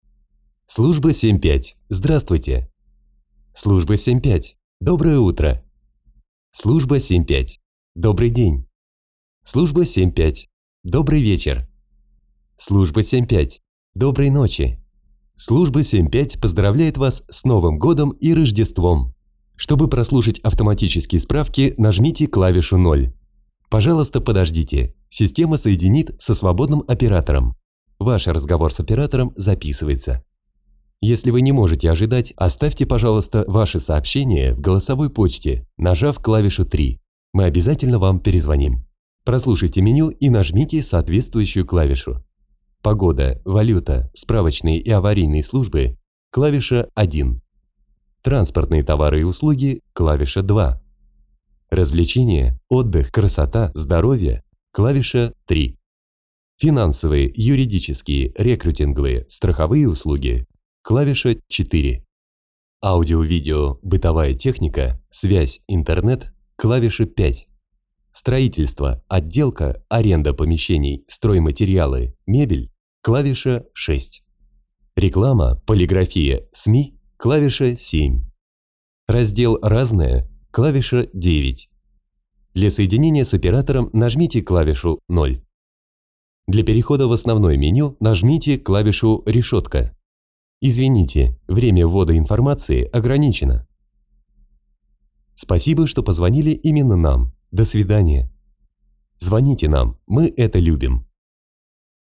IVR для справочной [Служба 75] Категория: Аудио/видео монтаж